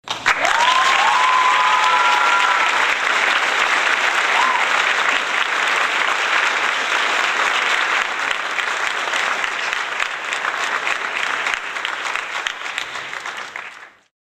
Applause Sound Effect Free Download
Applause